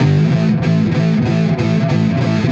Index of /musicradar/80s-heat-samples/95bpm
AM_HeroGuitar_95-A01.wav